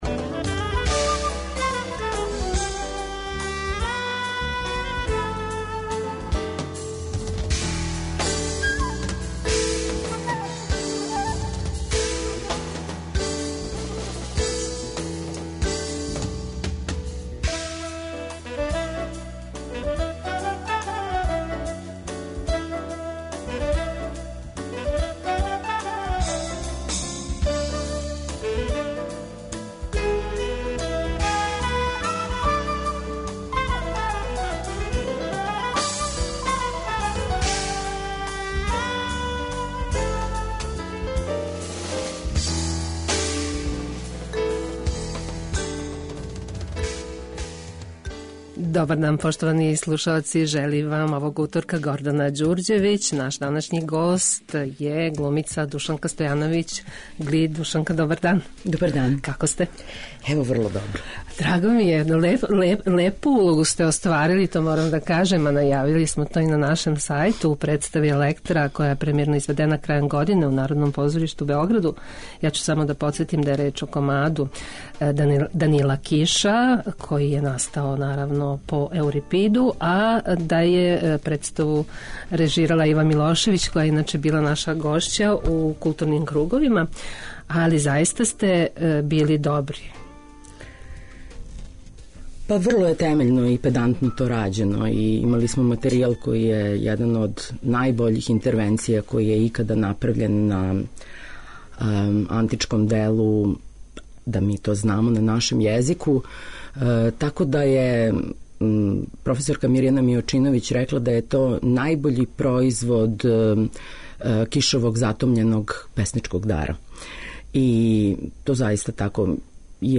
Гост 'Клуба 2' је Душанка Стојановић Глид, првакиња драме Народног позоришта у Београду.